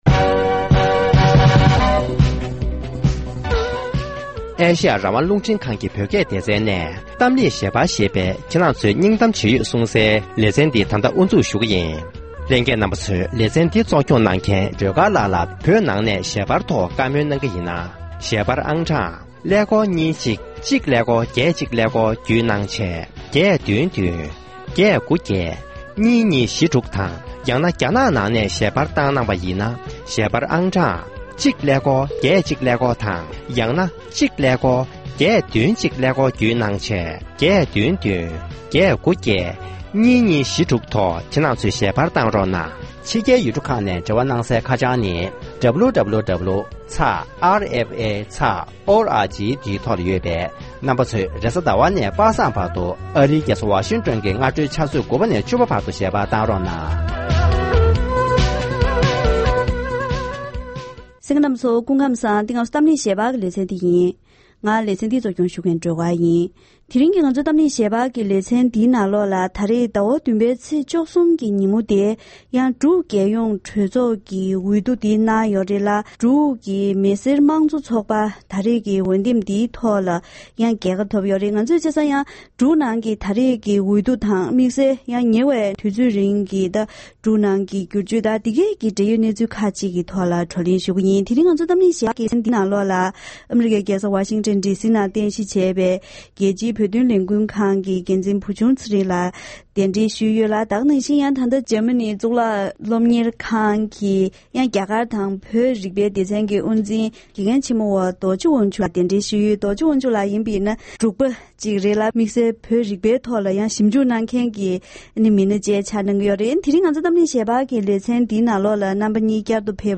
༄༅༎དེ་རིང་གི་གཏམ་གླེང་ཞལ་པར་ལེ་ཚན་ནང་ད་རེས་ཚེས་༡༣ཉིན་འབྲུག་རྒྱལ་ཡོངས་གྲོས་ཚོགས་ཀྱི་འོས་བསྡུ་གནང་ནས་འབྲུག་མི་སེར་དམངས་གཙོ་ཚོགས་པར་འོས་བསྡུའི་རྒྱལ་ཁ་ཐོབ་ཡོད་པས་ད་ཐེངས་ཀྱི་འོས་བསྡུའི་གནས་ཚུལ་དང་འབྲུག་རྒྱལ་ཁབ་ཀྱི་ཕྱི་འབྲེལ། མ་འོངས་ཁ་ཕྱོགས་དགོས་པའི་གནད་དོན་སོགས་འབྲེལ་ཡོད་གནས་ཚུལ་ཁག་གི་ཐོག་འབྲེལ་ཡོད་དང་ལྷན་བགྲོ་གླེང་ཞུས་པ་ཞིག་གསན་རོགས་གནང་།།